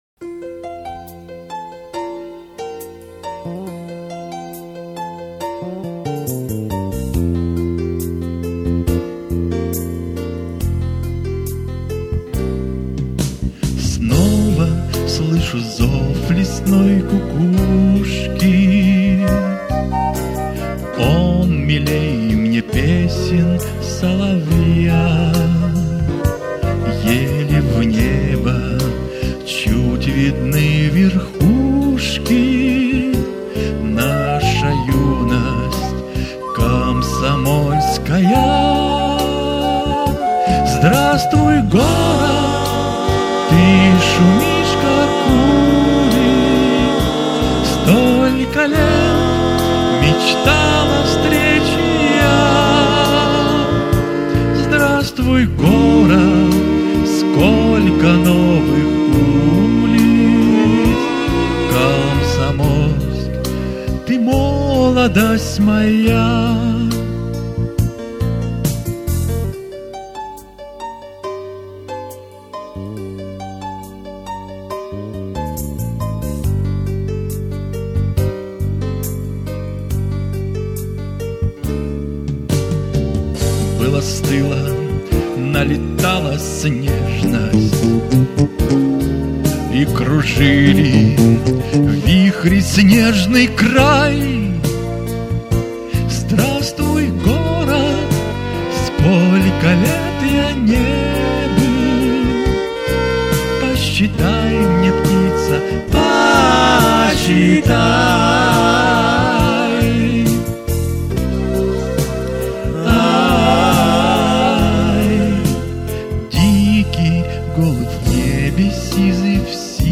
Песня на стихи Выборова К. Р. «Здравствуй, Город!